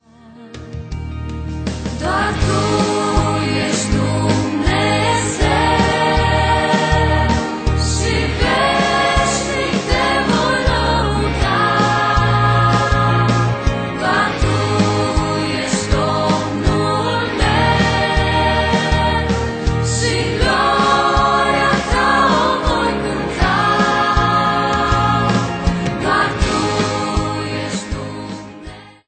intr-un stil propriu si revigorant